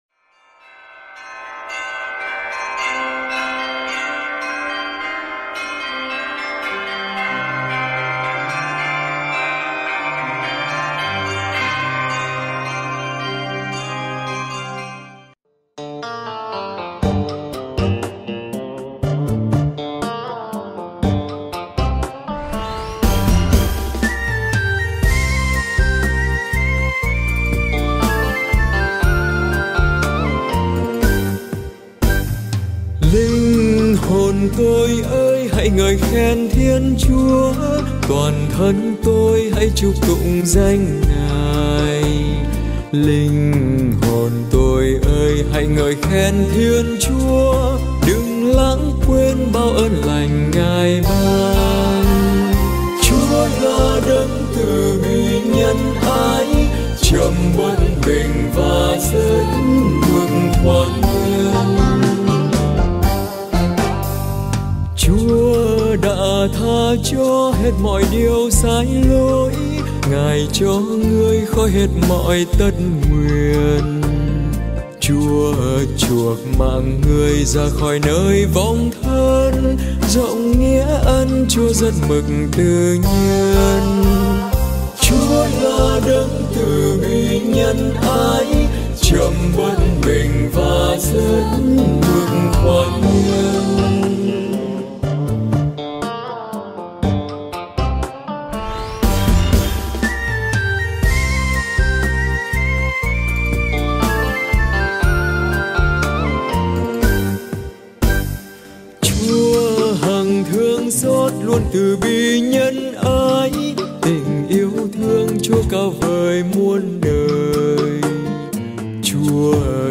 Thể loại 🌾 Nhạc Thánh Ca, 🌾 Thánh Vịnh - Đáp Ca